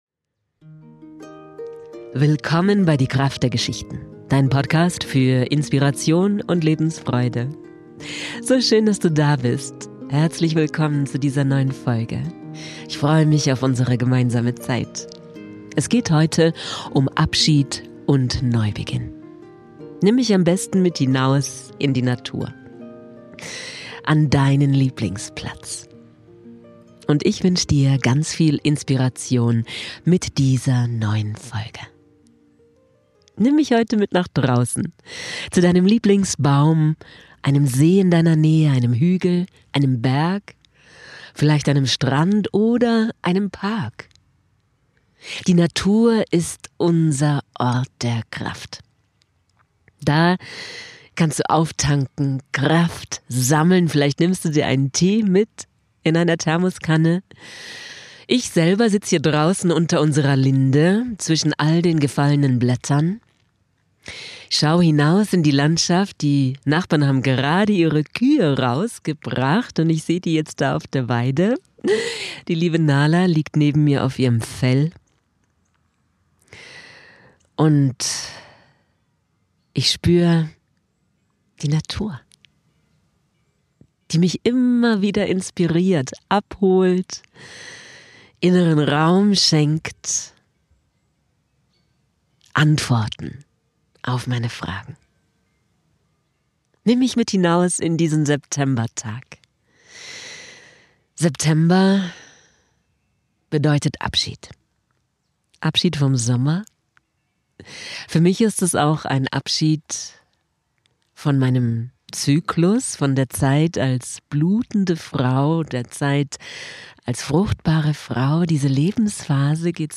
Da dachte ich mir, ich geh nach draußen in den Herbst und mach einen Podcast für Dich.
Ich hab eine Meditation für Dich aufgenommen.